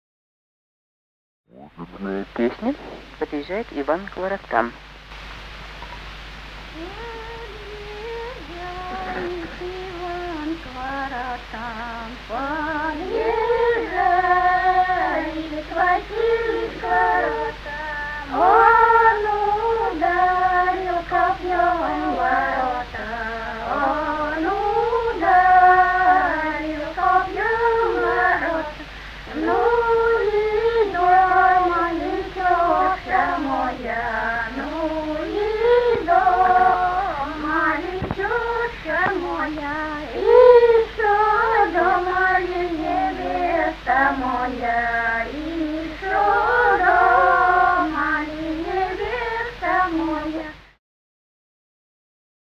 Русские народные песни Владимирской области [[Описание файла::7. Подъезжает Иван к воротам (свадебная) д. Галанино Судогодского района Владимирской области.